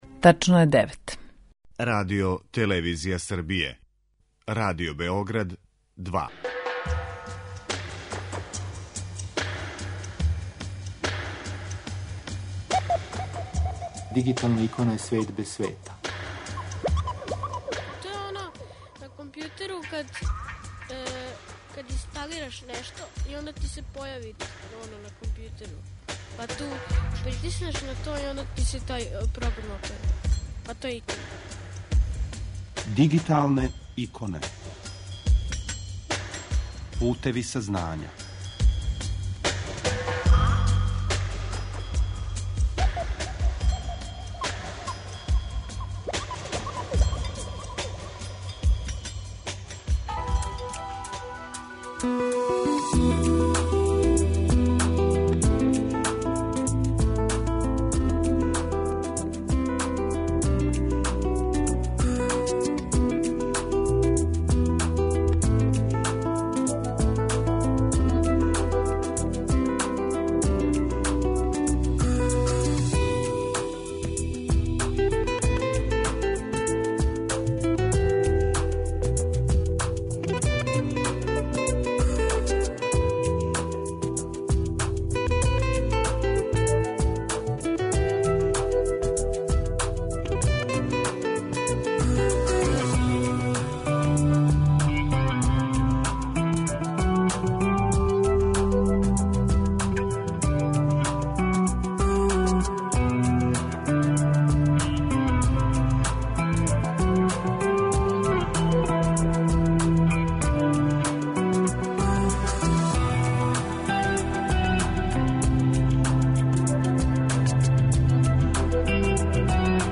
У другом делу емисије најавићемо скуп "Ћирилица на поклон" којим Регистар националног интернет домена Србије обележава седму годишњицу ћириличког .СРБ домена, а чућемо и кратку белешку са скупа којим је јуче обележен Дан заштите података о личности.